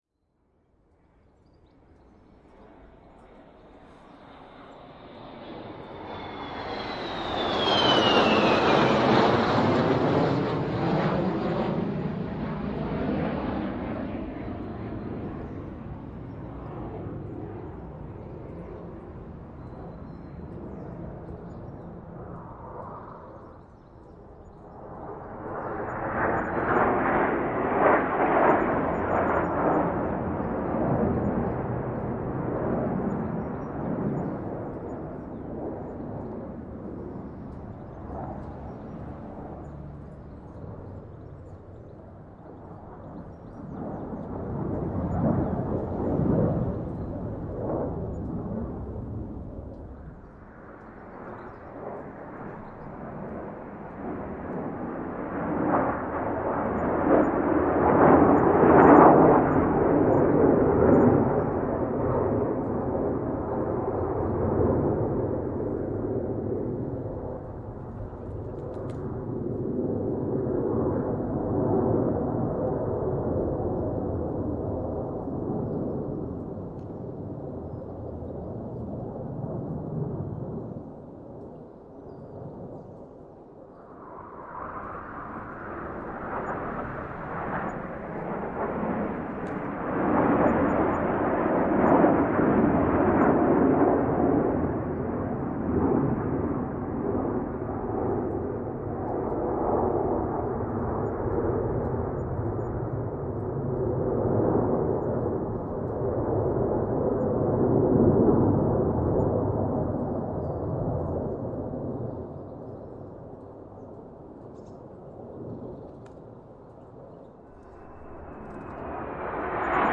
氛围 " Air FX黑胶
描述：乙烯基声音
标签： 劳斯莱斯 飞越 飞机 飞行 飓风 径向 野马 成像 还原飞机 V12 二战 英国 复古 FX 赖特 惠普 战斗机 梅林 Warbird 航展
声道立体声